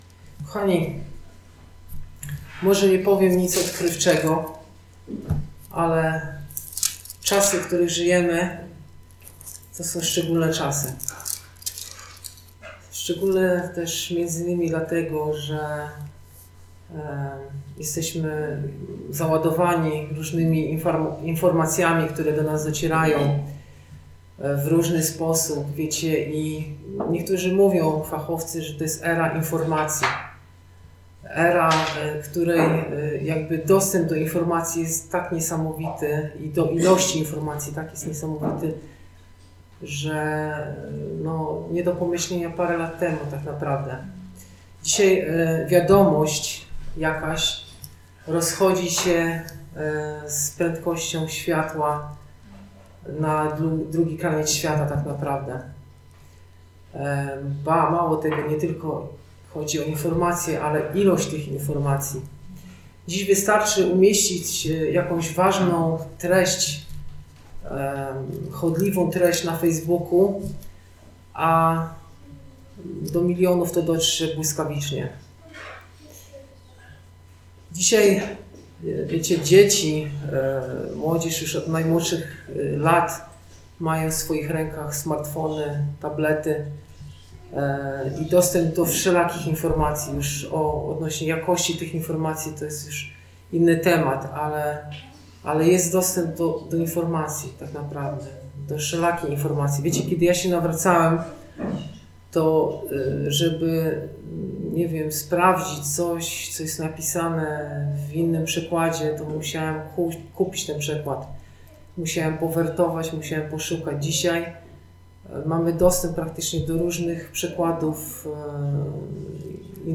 Posłuchaj kazań wygłoszonych w Zborze Słowo Życia w Olsztynie